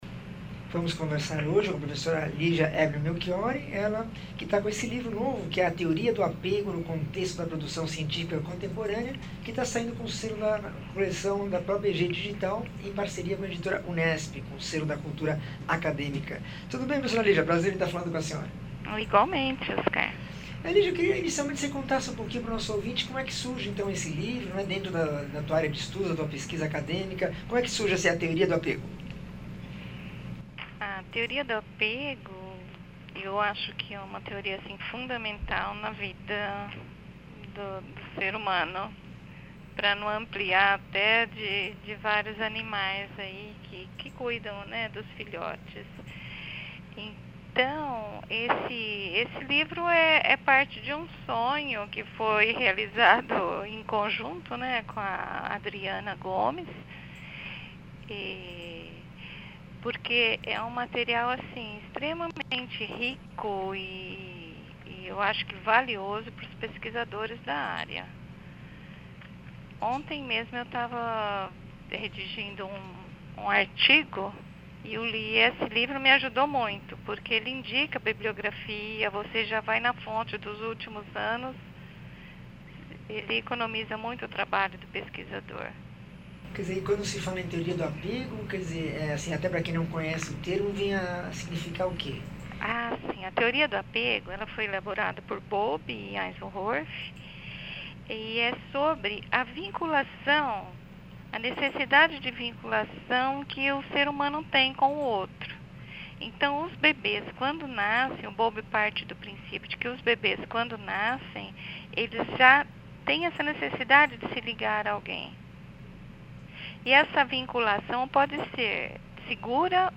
entrevista 1406